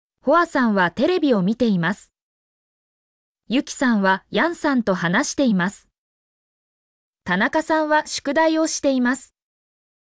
mp3-output-ttsfreedotcom-19_OeZqV1oB.mp3